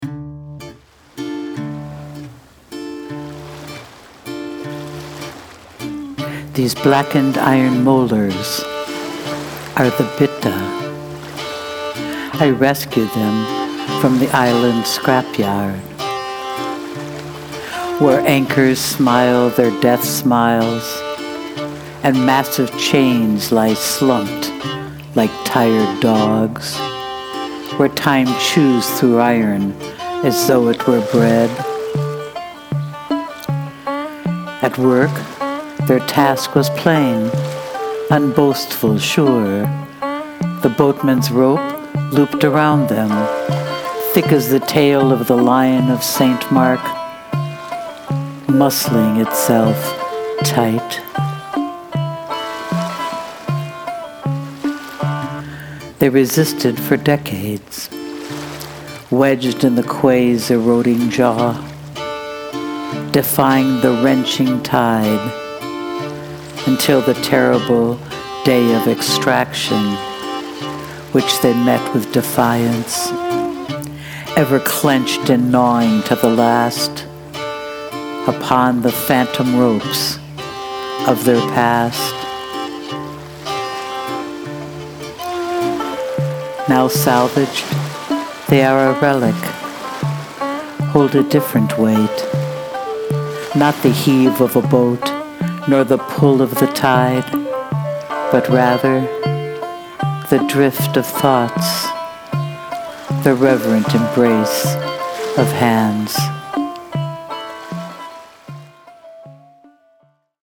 Your music and voice beautifully rendering the poem.